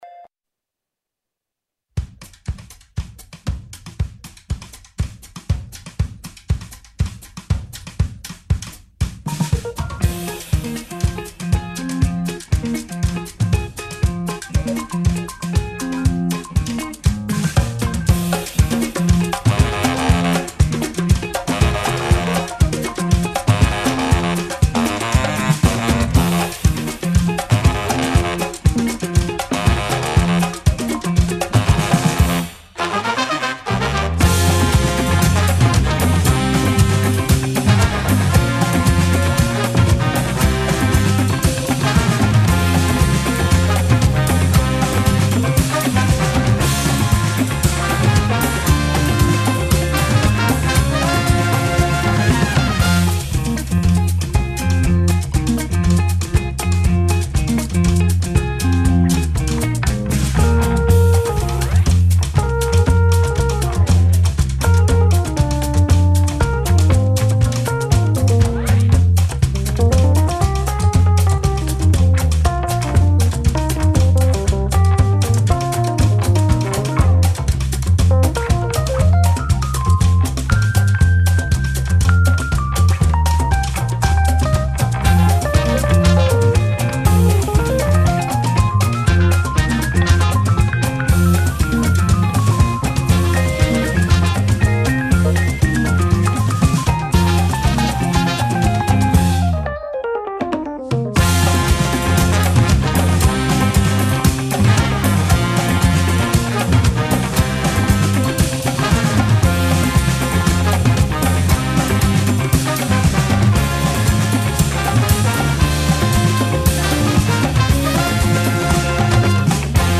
Oggi diretta da Fa' la cosa giusta!